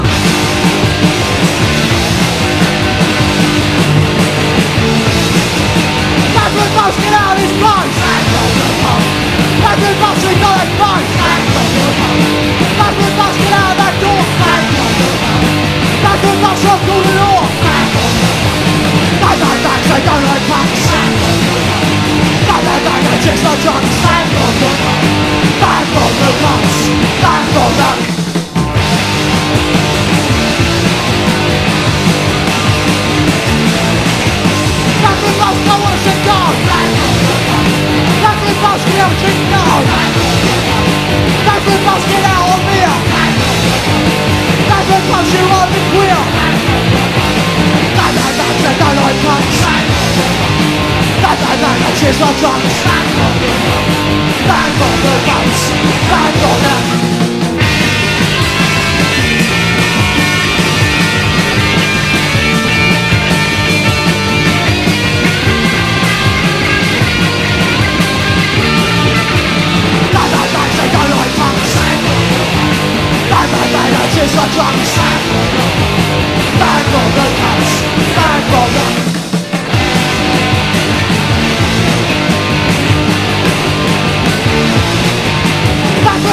ROCK / PUNK / 80'S～ / 90'S PUNK (GER)
甘酸っぱい青春泣きメロ・パンク/パワーポップ
ガレージ・パンク・インストロな仕上がりです！